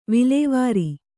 ♪ vilēvāri